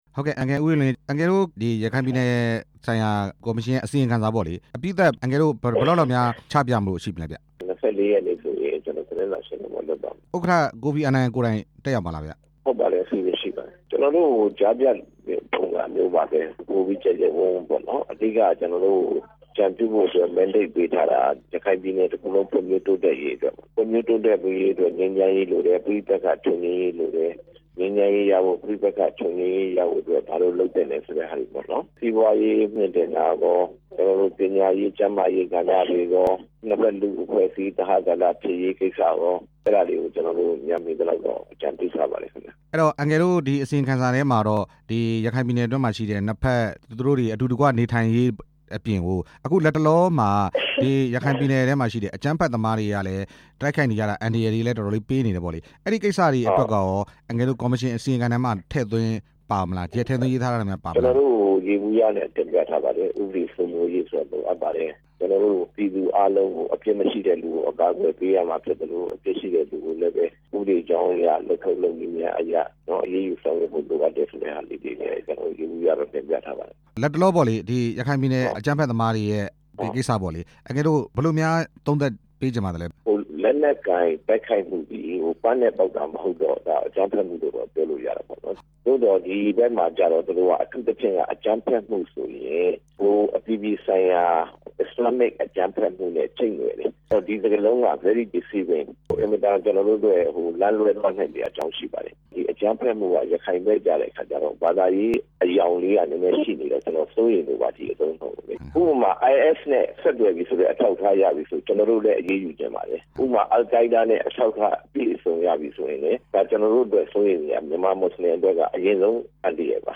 ရခိုင်ပြည်နယ်ဆိုင်ရာ အကြံပေးကော်မရှင် အဖွဲ့ဝင် ဦးအေးလွင်နဲ့ မေးမြန်းချက်